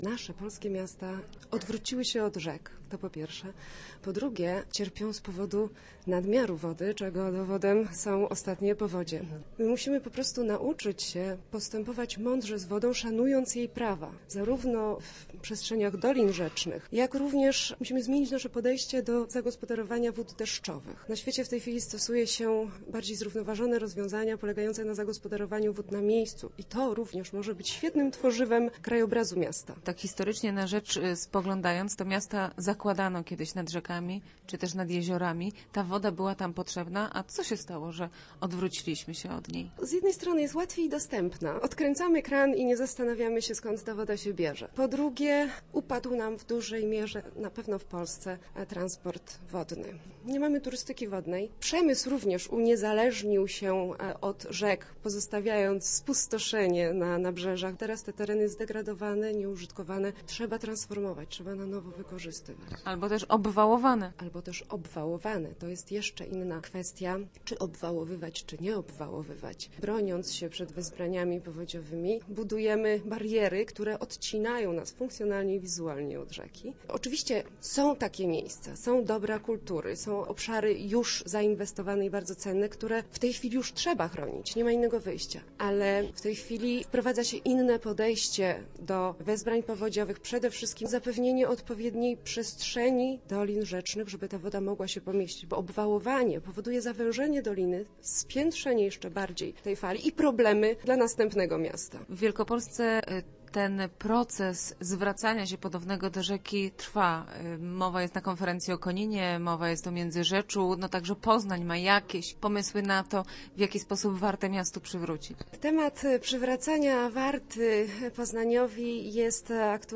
5aka9lld90bfkc8_woda_w_miescie_rozmowa.mp3